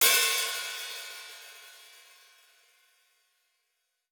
TC2 Live Hihat4.wav